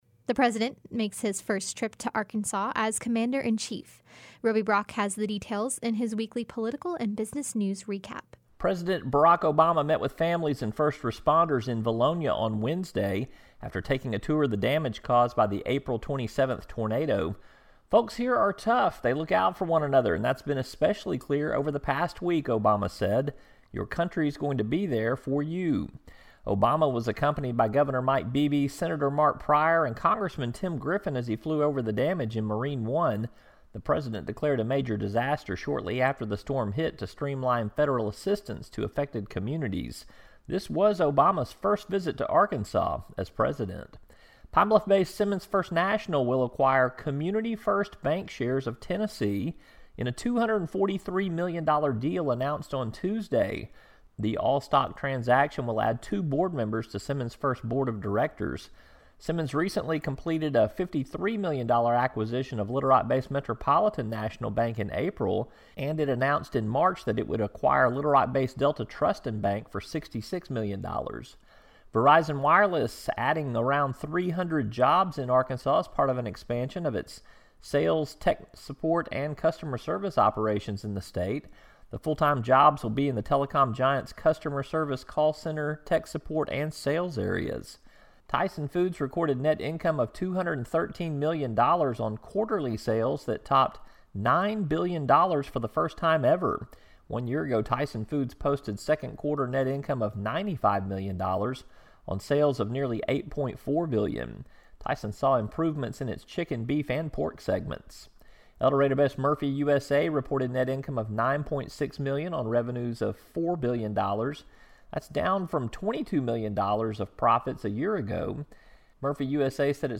and more in his weekly business and political news recap.